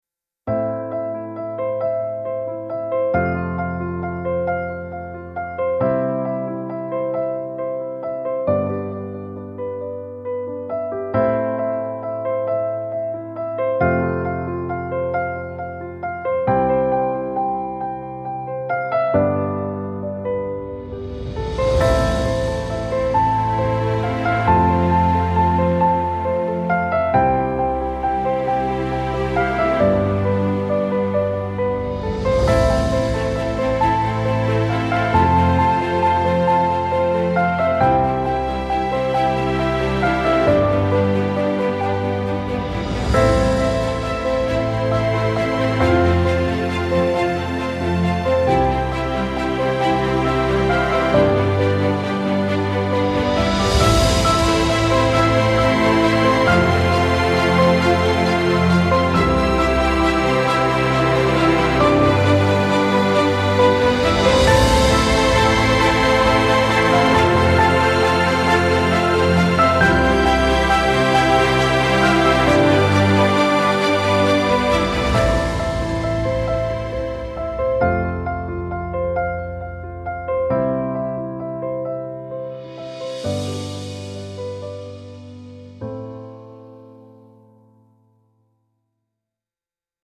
grand piano concerto style piece with sweeping dynamics and silk-like phrasing